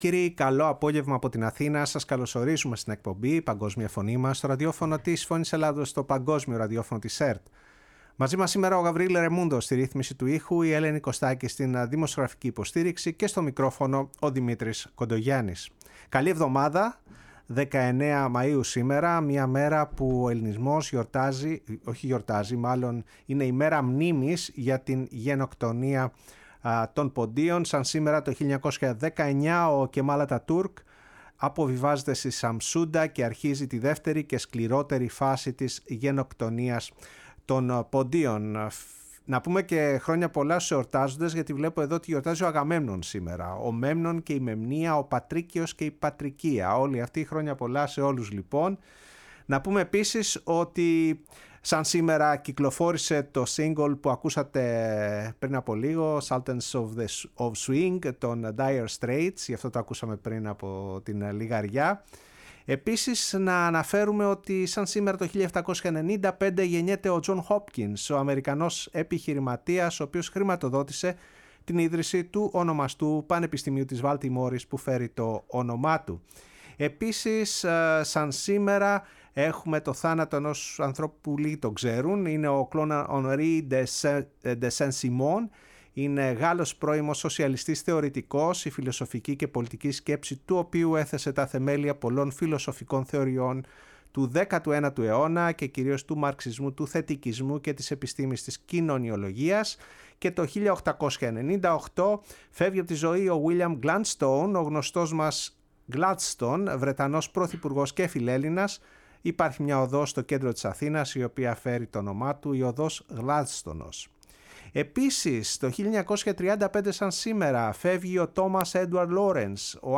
βρέθηκε στο στούντιο του Ραδιοφώνου της Φωνής της Ελλάδας